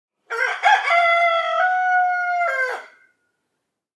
دانلود آهنگ مرغ 4 از افکت صوتی انسان و موجودات زنده
جلوه های صوتی
دانلود صدای مرغ 4 از ساعد نیوز با لینک مستقیم و کیفیت بالا